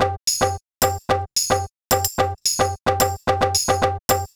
106 BPM Beat Loops Download